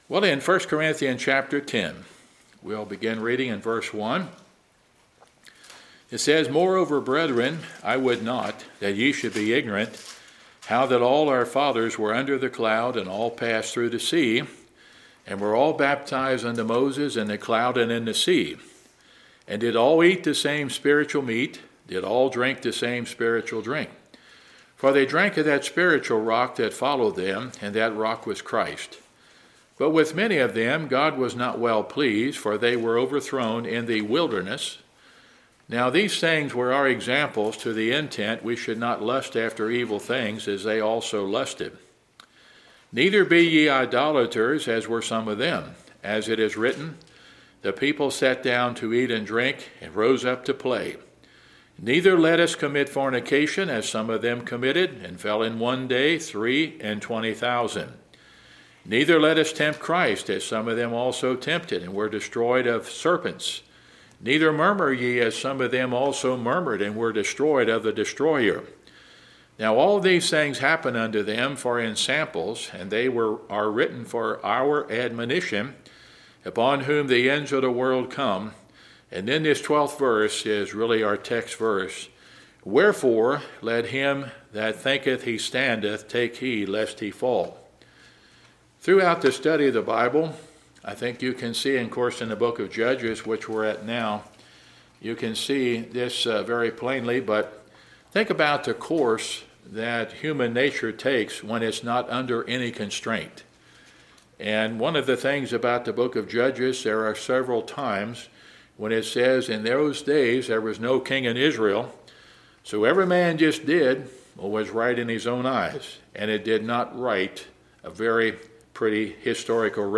2024 Series: Wednesday Sermon Book